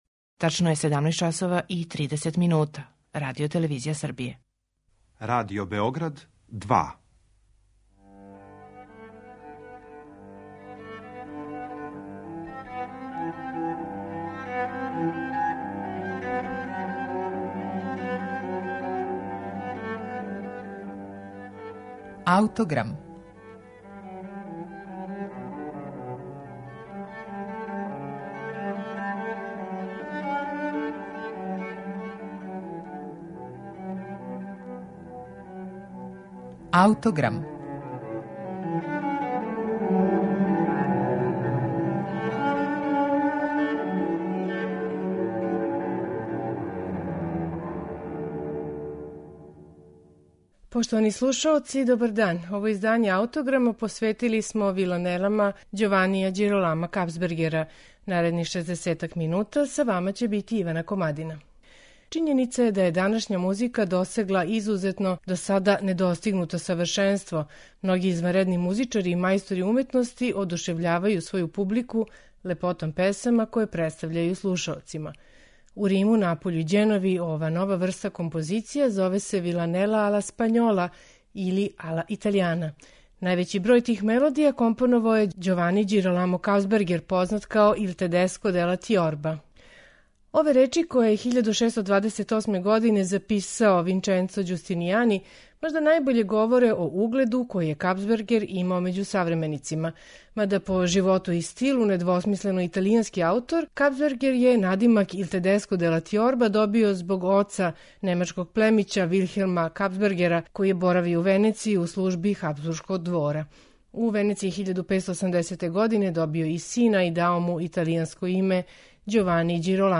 Виланеле Ђованија Ђиролама Капсбергера
сопран
тенори
барокна харфа, теорба, барокна гитара